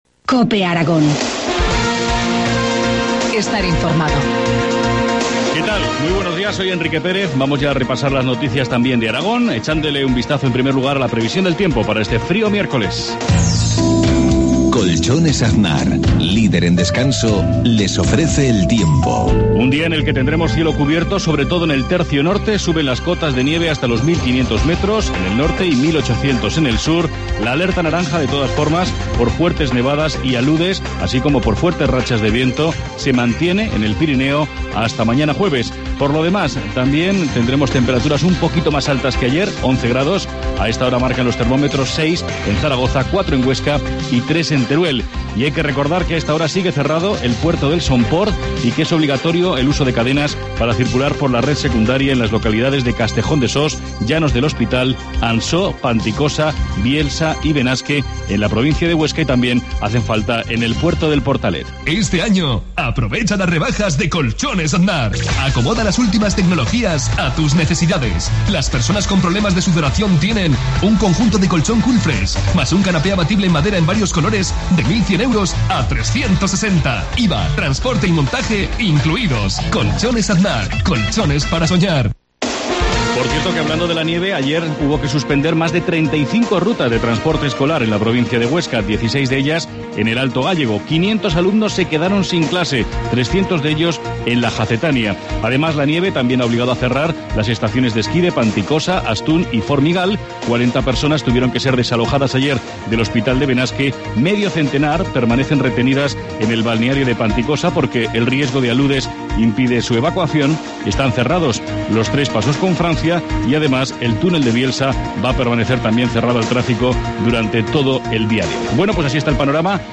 Informativo matinal, miércoles 16 de enero, 7.53 horas